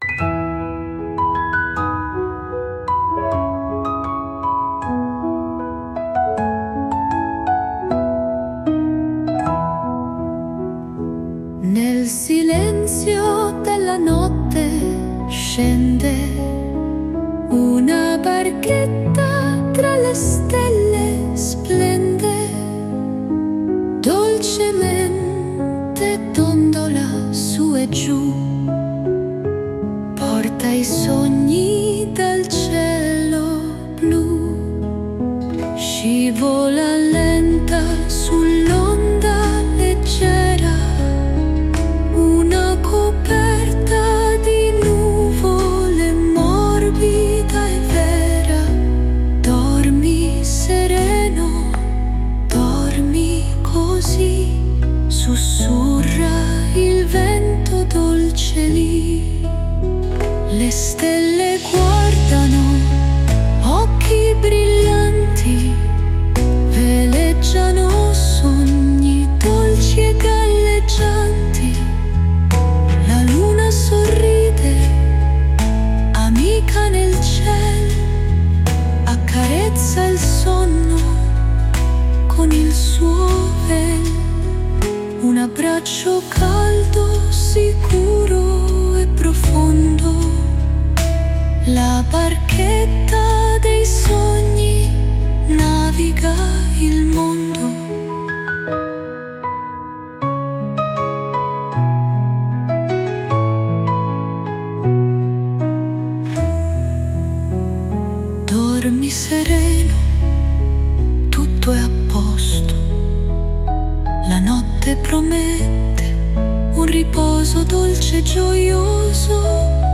🎵 Nanna📺 GUARDA